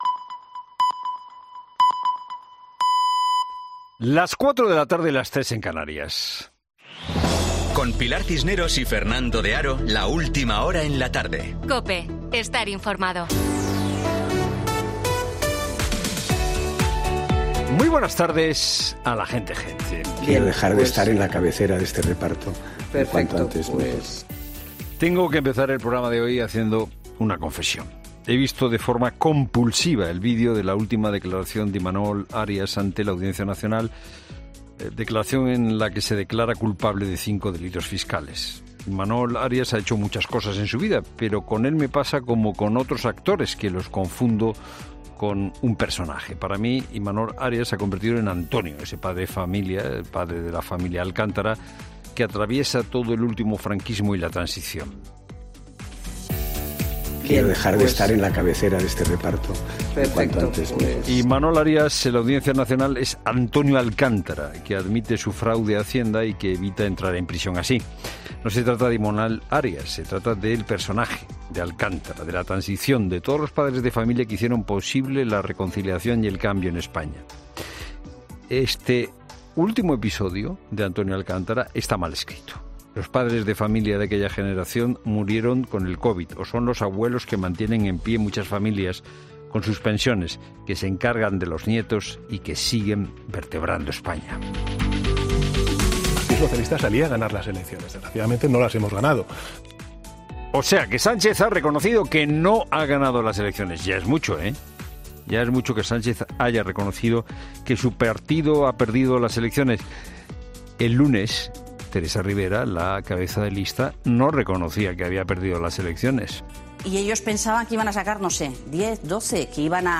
Monólogo de Fernando de Haro